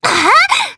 Viska-Vox_Casting3_jp.wav